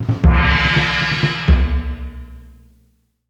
gamestart.ogg